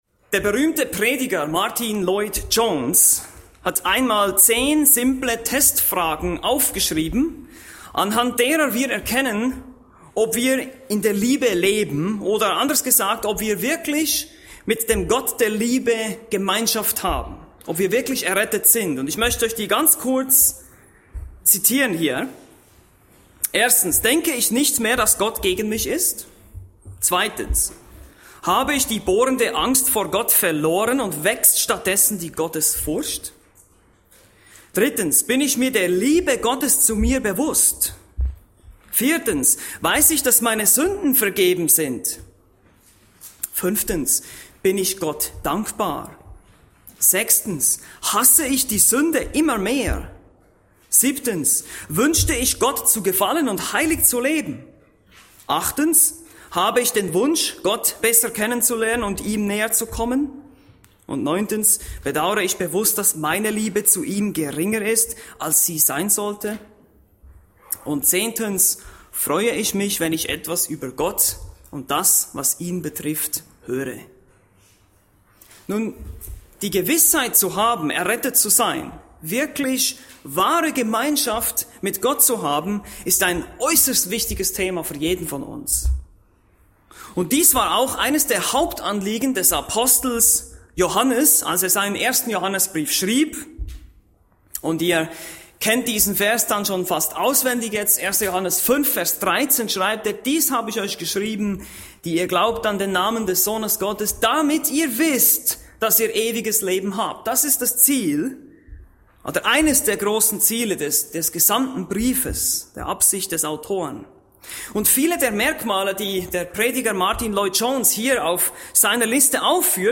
Bibelstunden - Bibelgemeinde Barnim